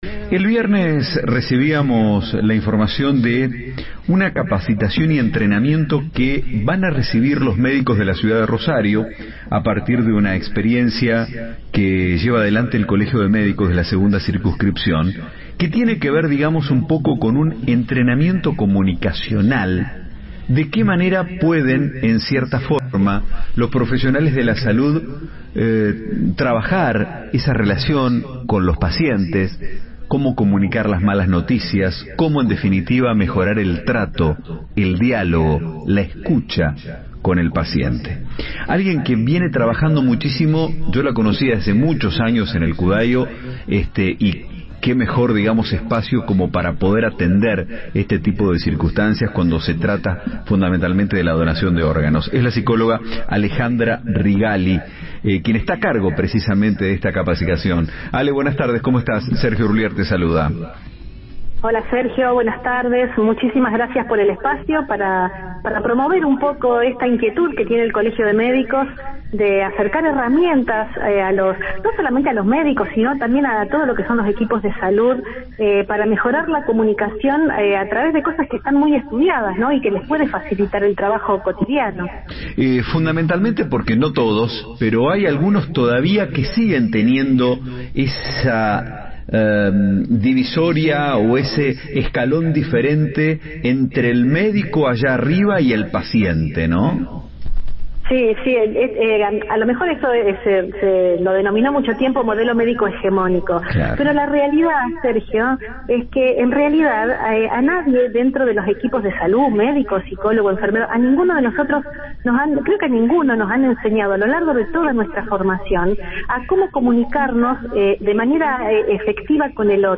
Nota exclusiva por Radio 2 https